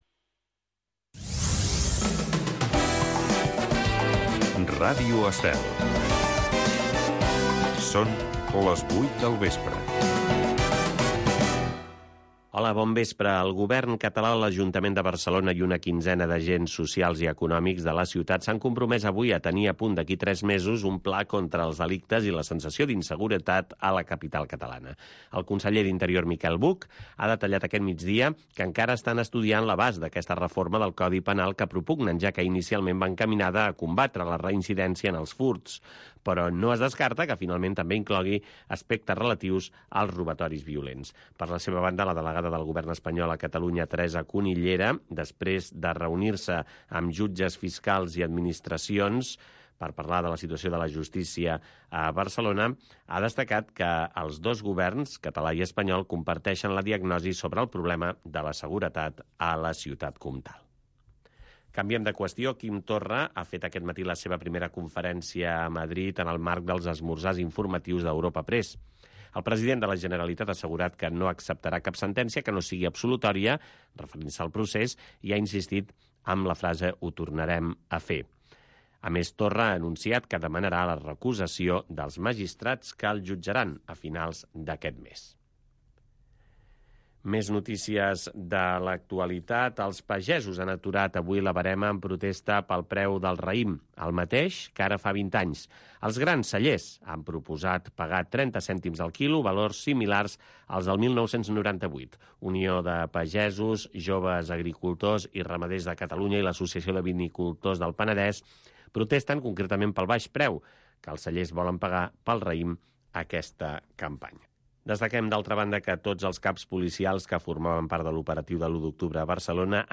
Un programa amb entrevistes i tertúlia sobre economia amb clau de valors humans, produït pel CEES